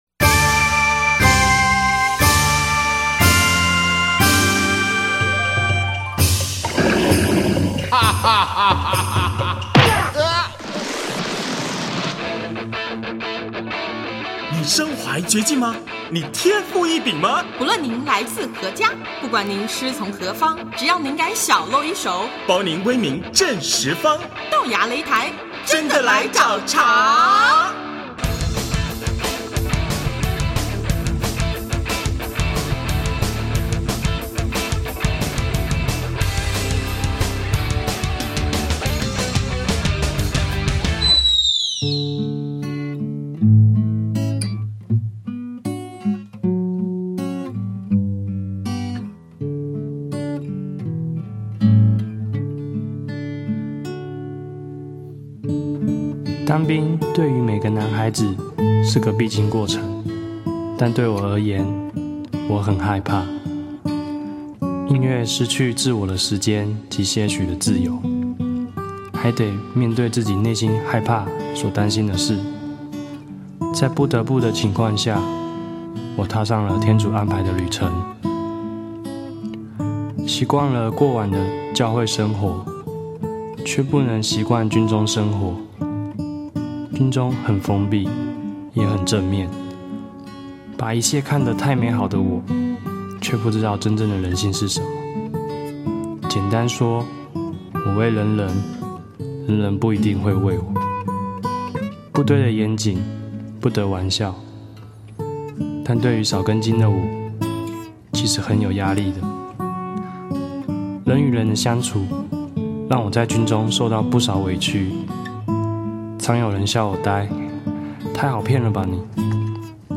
【豆芽擂台】80|专访怀仁乐团(二)：大家都爱你